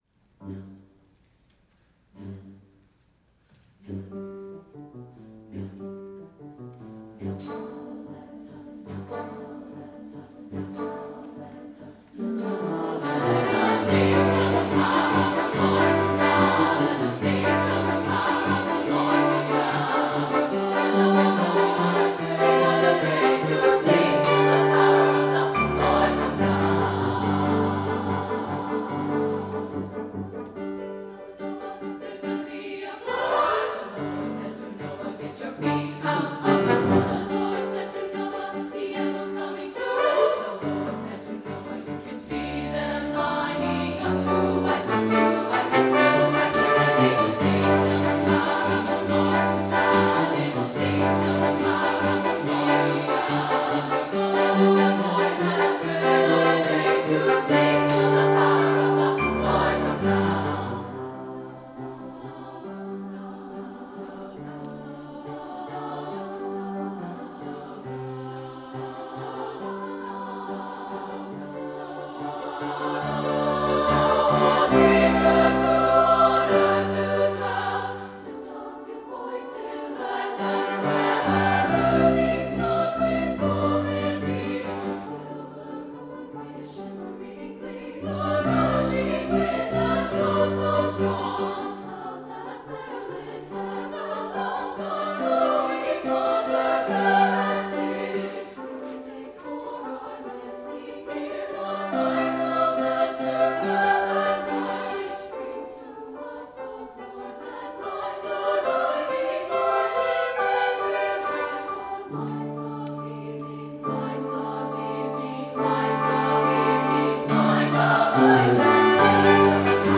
SSA, brass, percussion, and piano